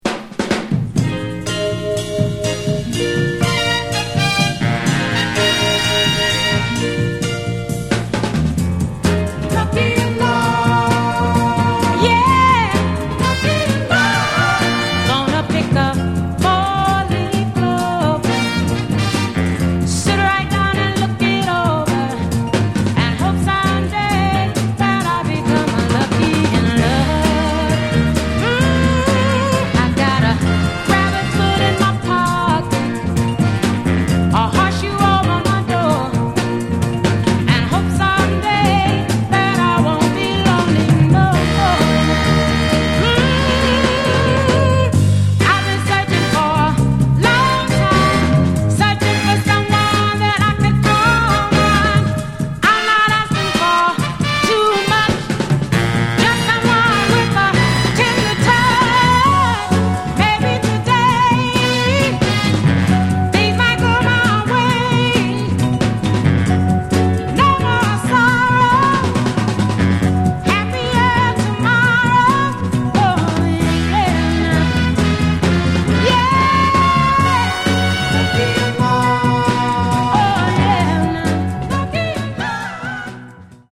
Genre: Other Northern Soul
and it is a great dancer you won't want to miss!